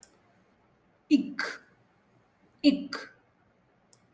ik